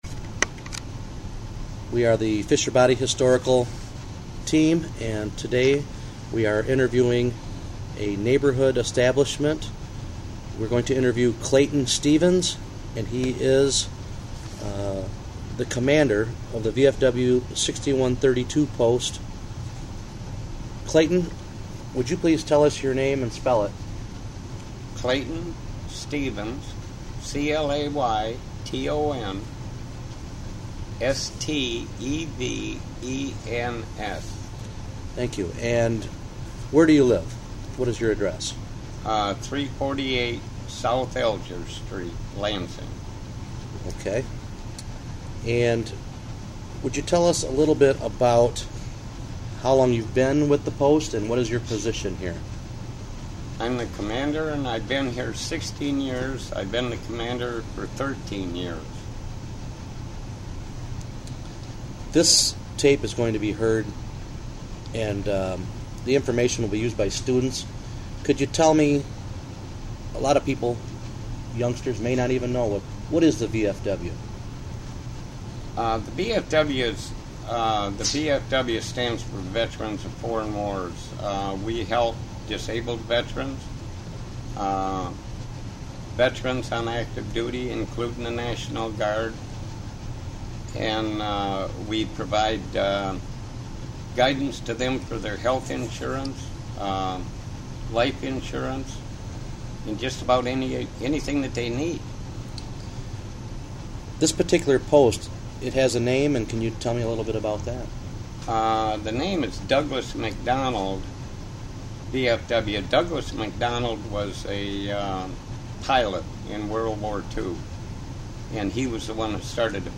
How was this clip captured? Recorded on Feb. 20, 2006 as part of the United Auto Workers Local 602/General Motors Oral History Project.